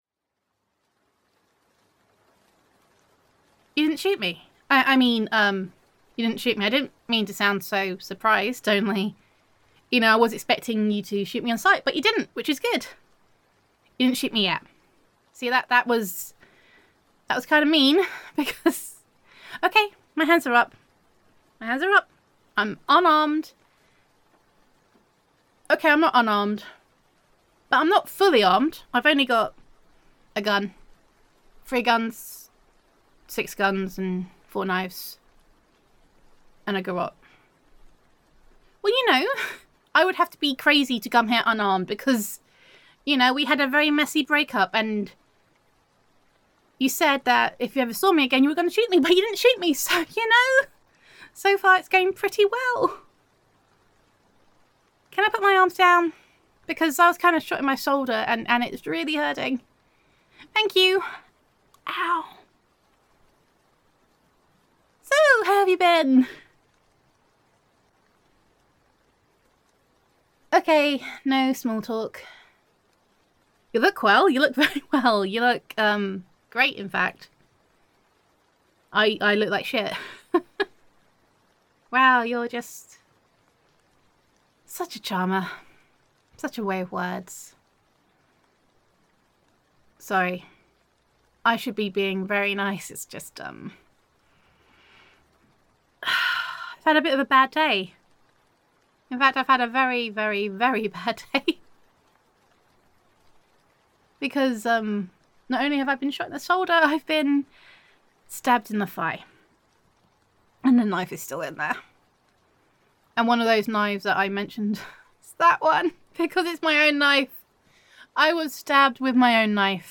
[F4A] Defective [Assassin Roleplay]